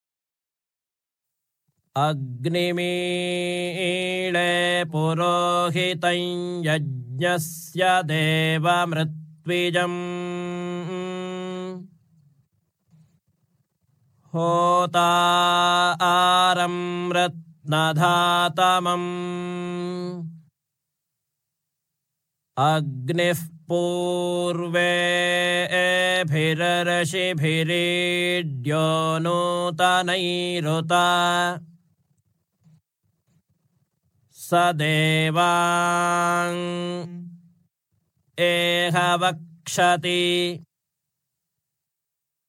Slišali boste, kako pravzaprav zveni recitacija Vede, ki predstavlja najbolj osnovno vibriranje čiste zavesti in zagotovo boste takoj začutili, da ima poslušanje Vede zelo močen učinek na poslušalca!
Agnim-Rig-Veda.mp3